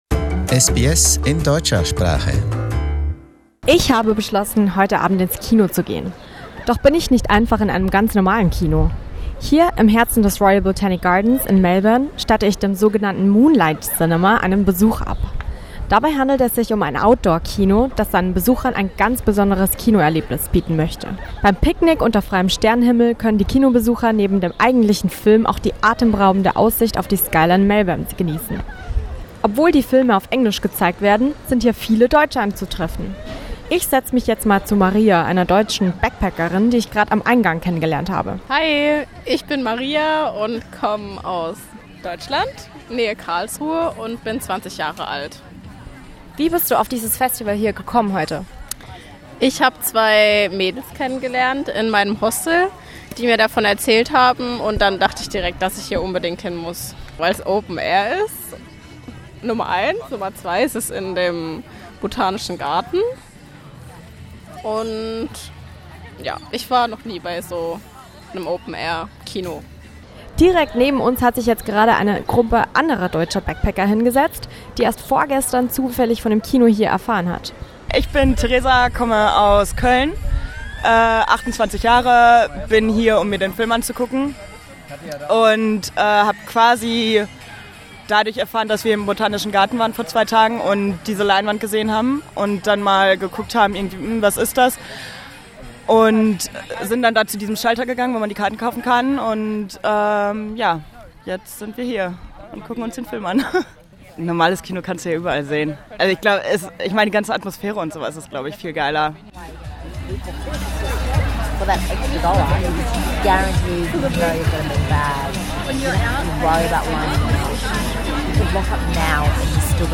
Surrounded by the beautiful plants of Royal Botanic Garden, visitors can enjoy a special movie night. We went along and spoke to some German visitors.